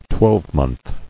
[twelve·month]